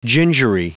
Prononciation du mot gingery en anglais (fichier audio)